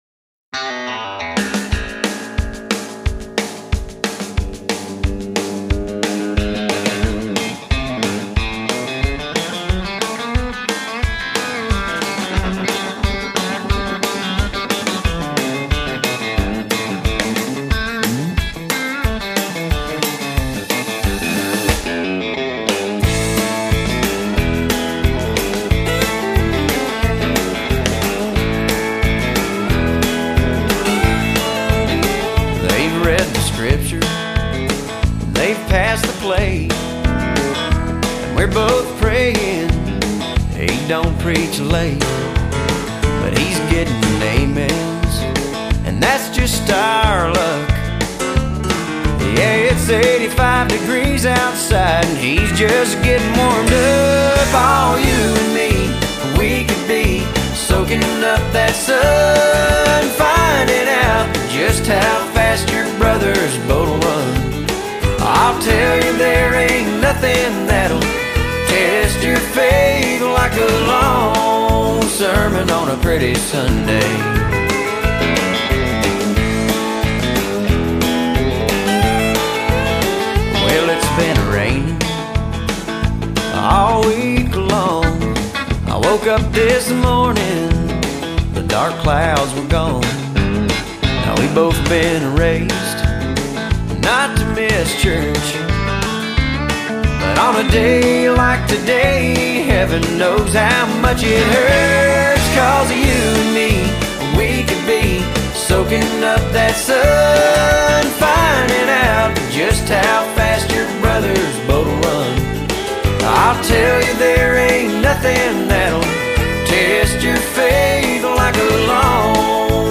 音乐类型：Country